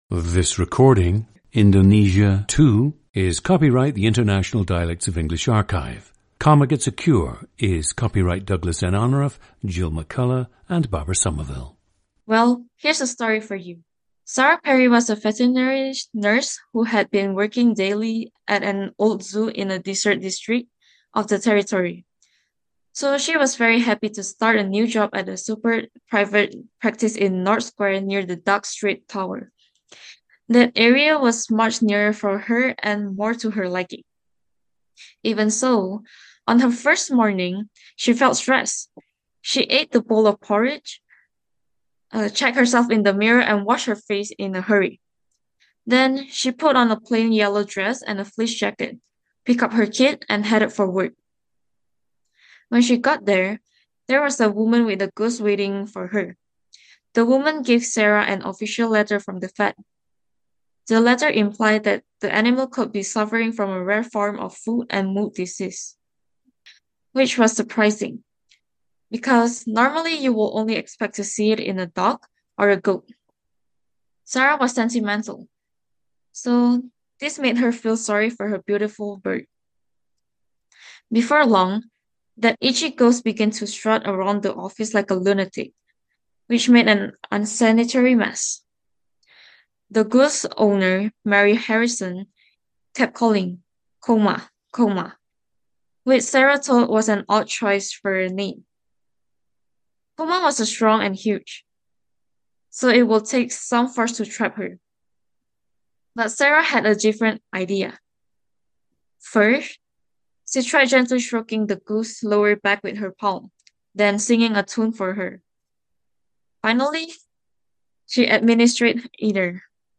GENDER: female
Growing up, the subject watched a lot of movies, which she says impacted her pronunciation and comfort with English.
• Recordings of accent/dialect speakers from the region you select.
The recordings average four minutes in length and feature both the reading of one of two standard passages, and some unscripted speech.